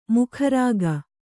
♪ mukha rāga